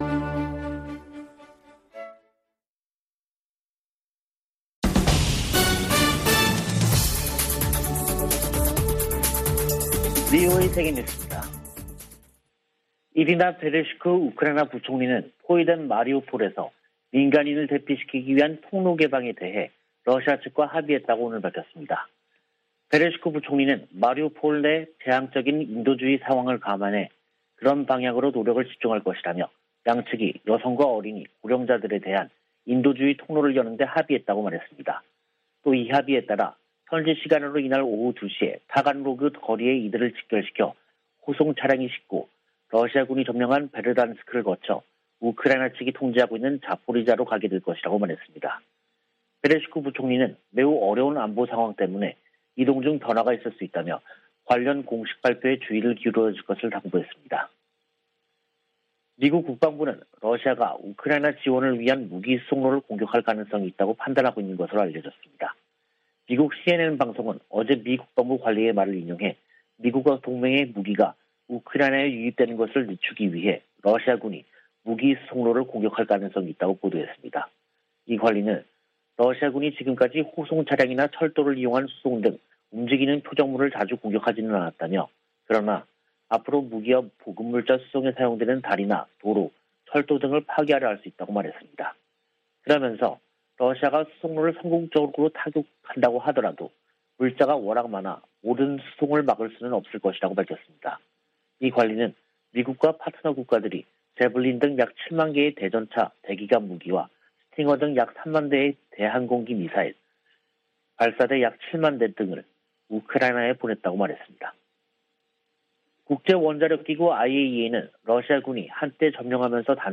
VOA 한국어 간판 뉴스 프로그램 '뉴스 투데이', 2022년 4월 20일 2부 방송입니다. 성 김 미 국무부 대북특별대표가 한국 윤석열 차기 정부의 박진 외교부 장관 후보자를 만나 북 핵 문제 등 공조 방안을 논의했습니다. 북한이 대량살상무기와 탄도미사일 개발 자금을 조달하기 위해 악의적 사이버 활동을 벌이고 있다고 백악관 당국자가 지적했습니다. 유럽연합은 북한의 신형 전술유도무기를 시험 주장과 관련해 강력한 제재 이행 기조를 확인했습니다.